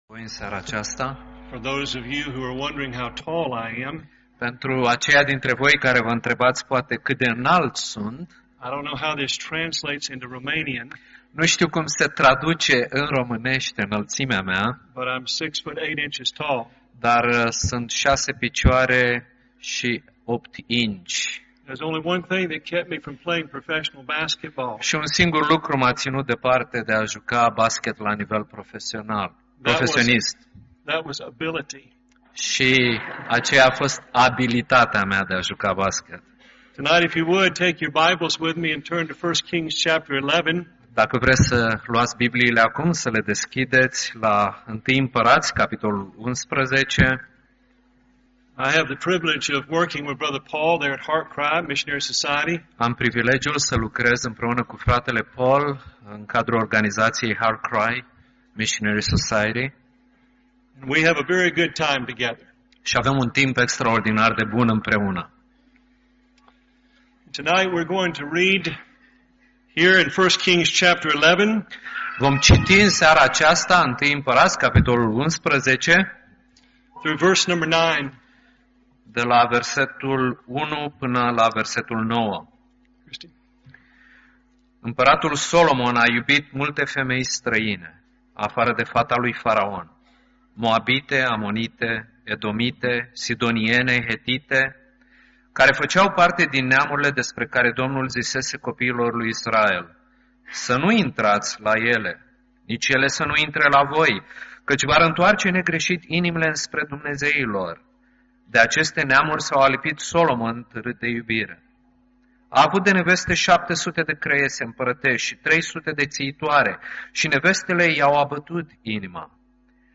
Conferința HeartCry 2010 (Brașov)